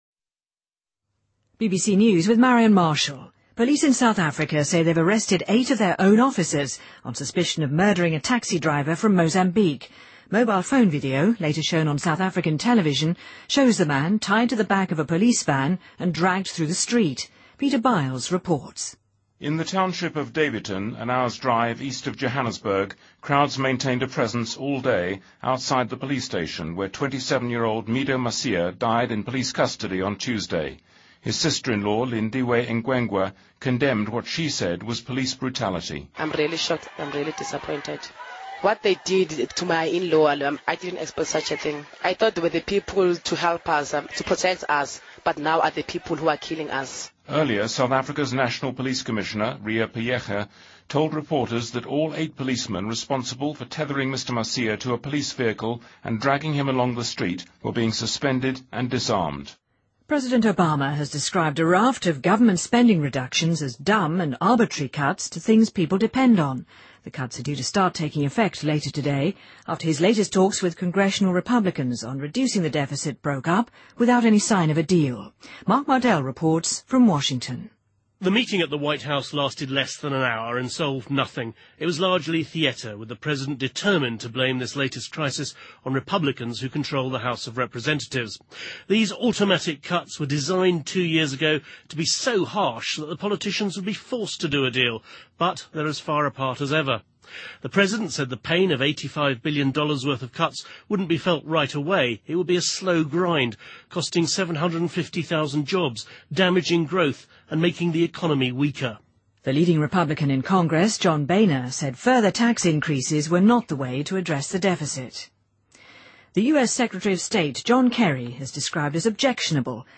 BBC news,2013-03-02